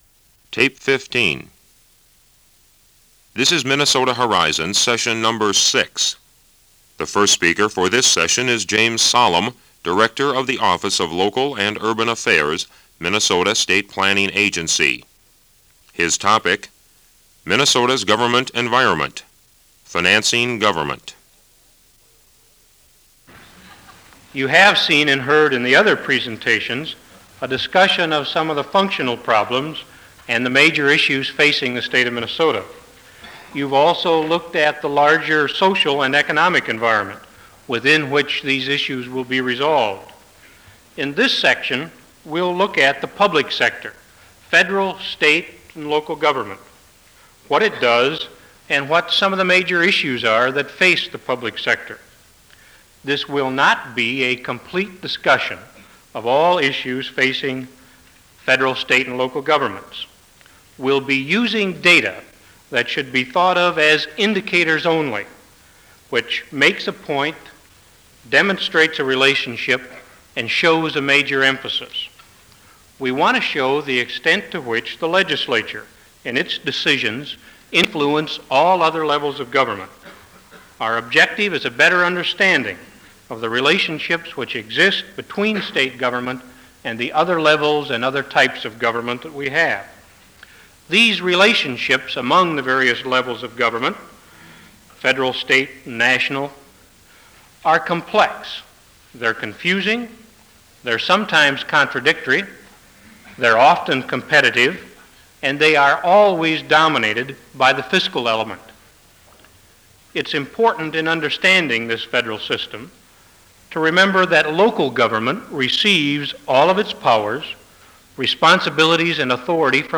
Location Internet Audio recordings: Note: sound quality can be poor due to lack of microphone use by speakers and meeting participants.